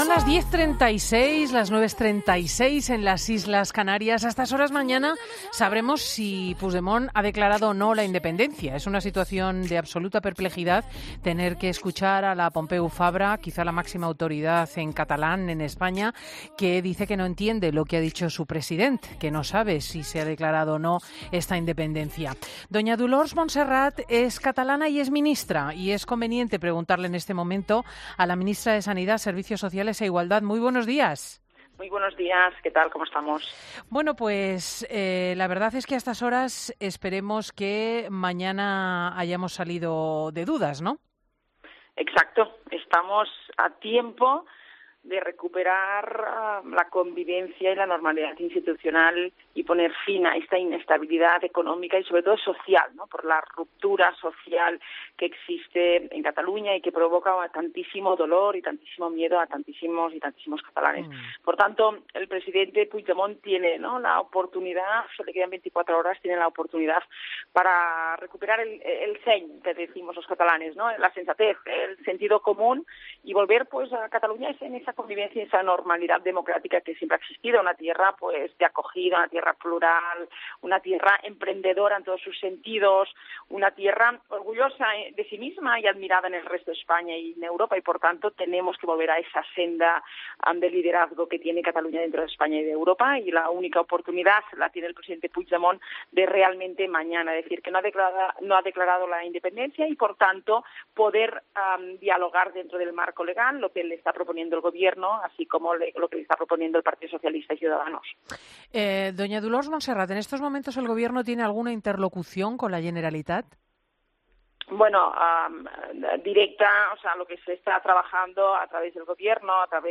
Escucha la entrevista a Dolors Montserrat en 'Fin de semana'